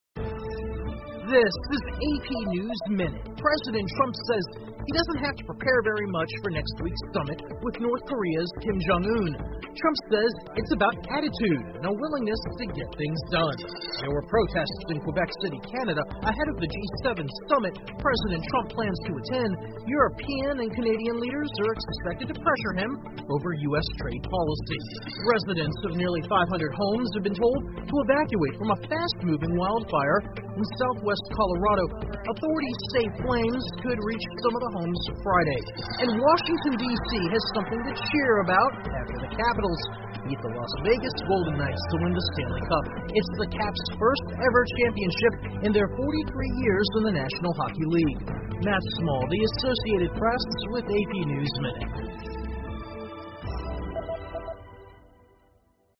美联社新闻一分钟 AP 七国集团峰会前魁北克游行 听力文件下载—在线英语听力室